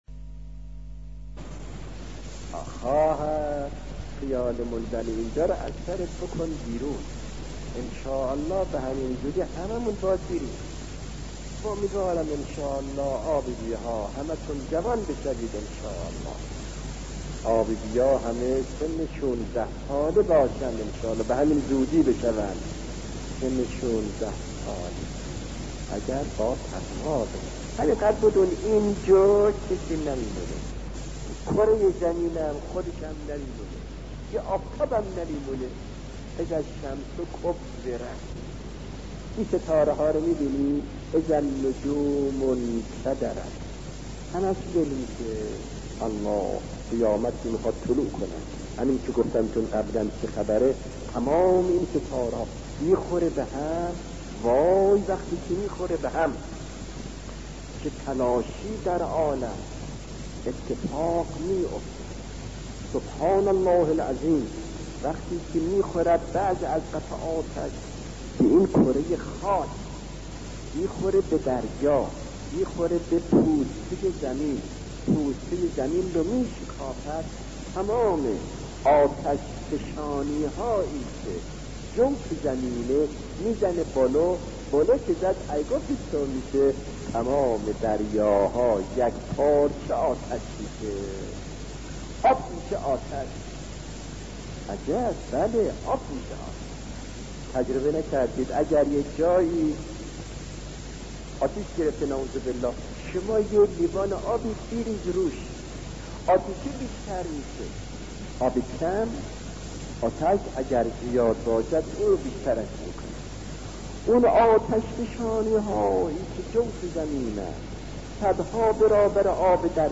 سخنرانی شهید آیت الله دستغیب- روز قیامت و تفسیر آیاتی از سوره تکویر